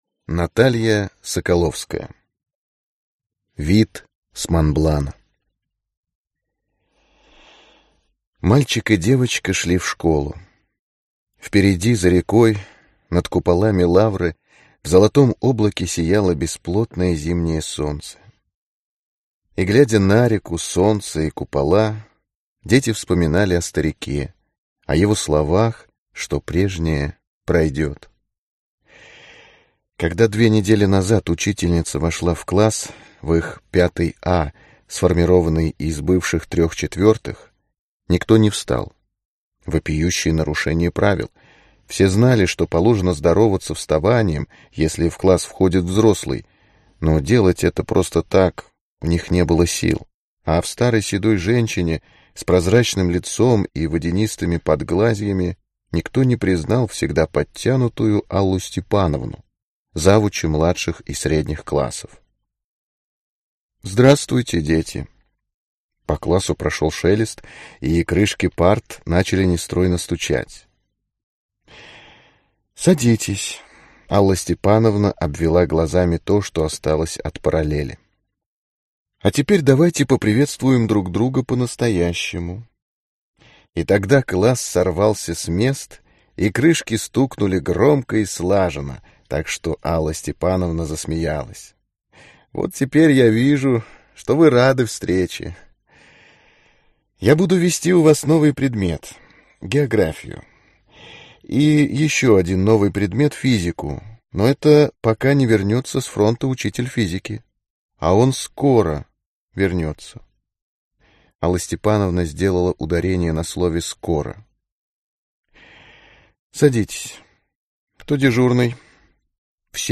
Аудиокнига Вид с Монблана | Библиотека аудиокниг